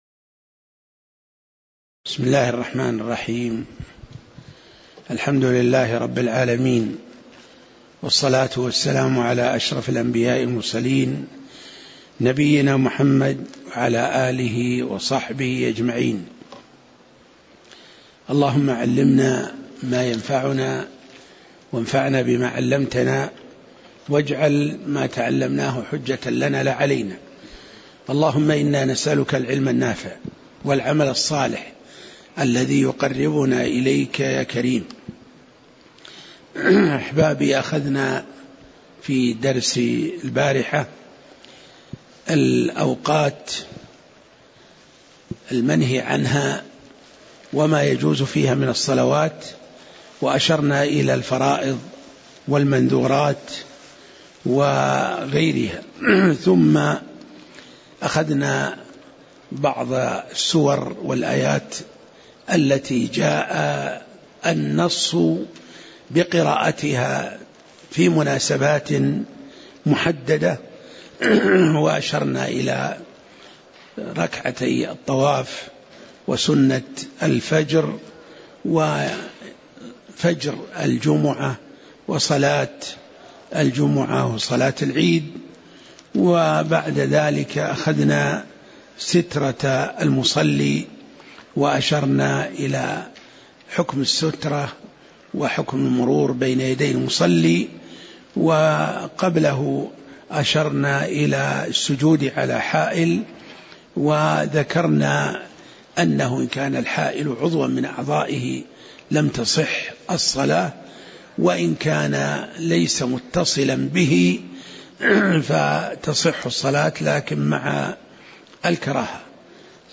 تاريخ النشر ١٧ شوال ١٤٣٨ هـ المكان: المسجد النبوي الشيخ